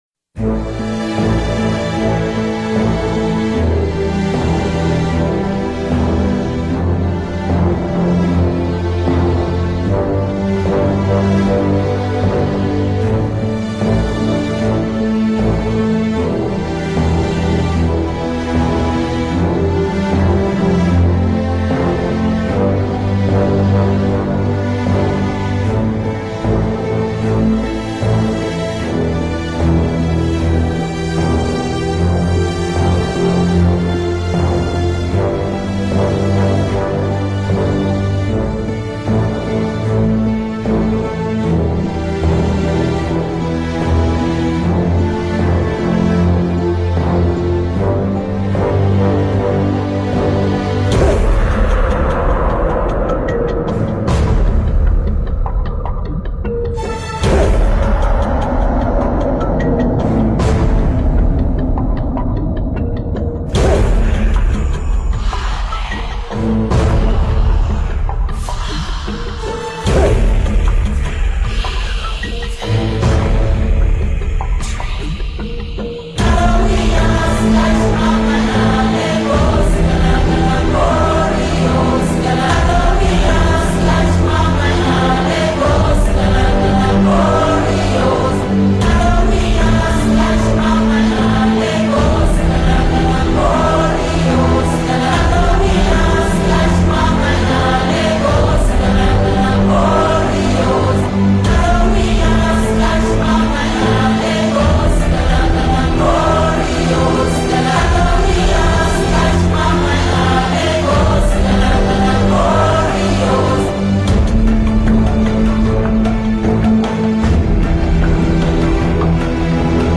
For humans, dwarves and elves it is too tribal, for orcs and scalefolks it is too peaceful.